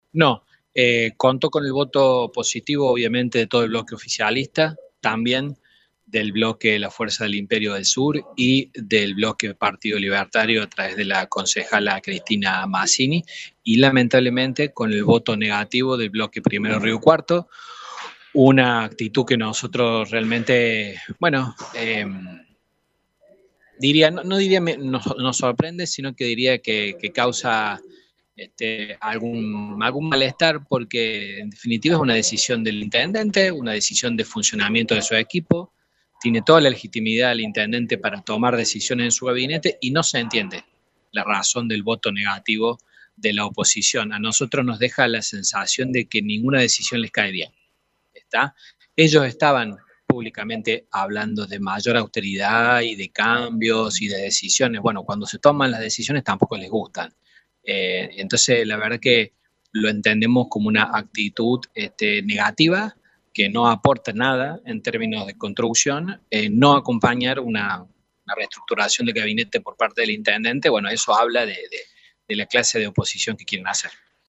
Al respecto, Carpintero cuestionó la postura de la oposición y dijo que la conformación del gabinete es una decisión del intendente y que no se entiende la razón del voto negativo.